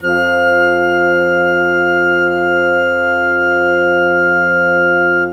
Rock-Pop 22 Bassoon _ Flute 01.wav